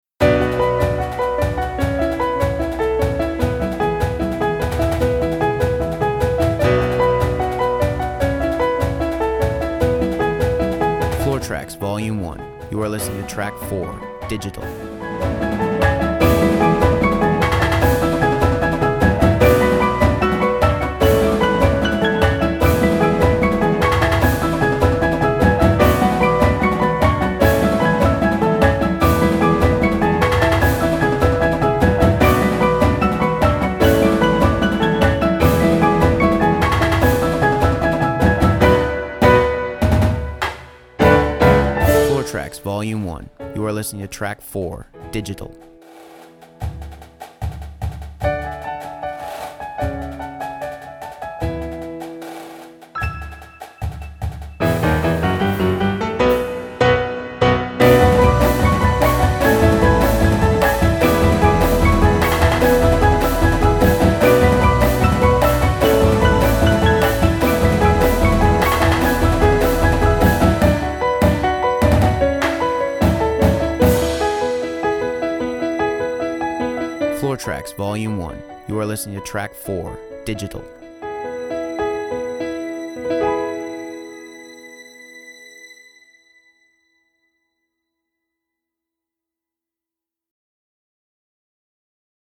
(with voiceover)   Purchase high-quality track